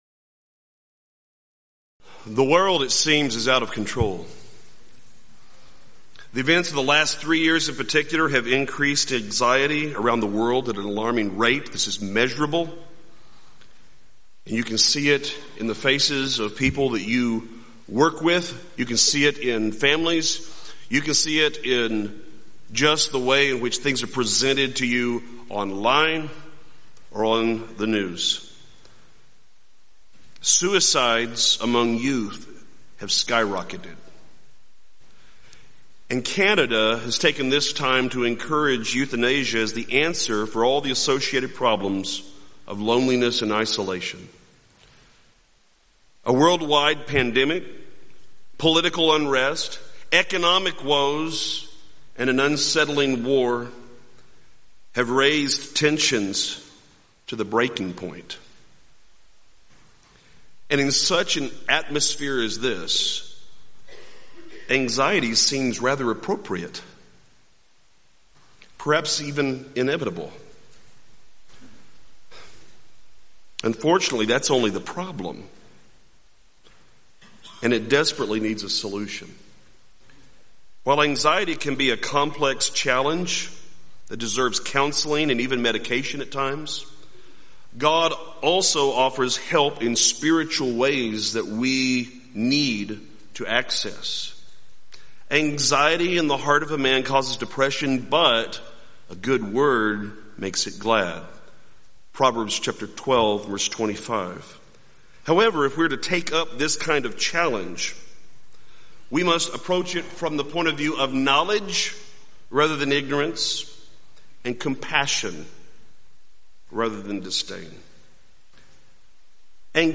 Event: 6th Annual Southwest Spiritual Growth Workshop
lecture